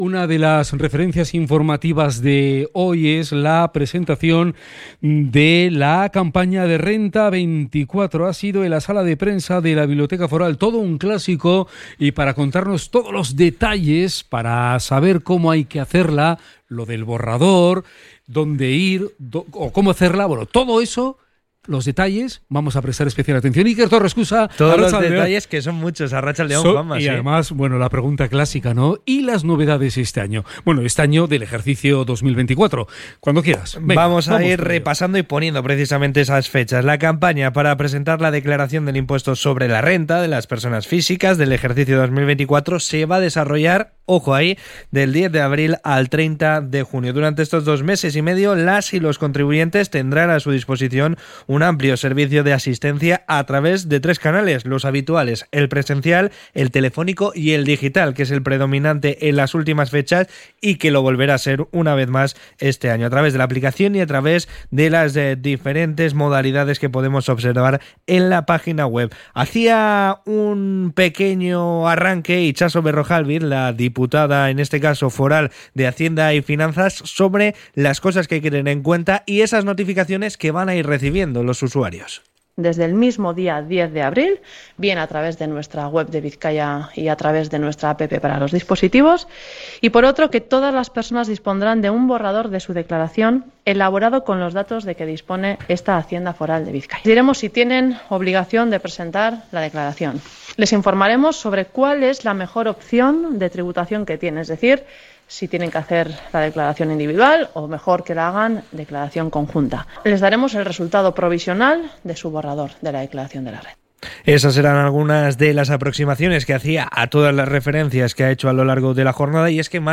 CRONICA-RENTA.mp3